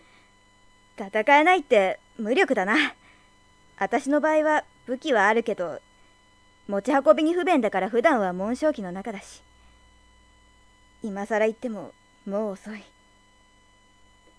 ＳＡＭＰＬＥ　ＶＯＩＣＥ
イメージではちょっと低めの声。勢いがあるといいかな。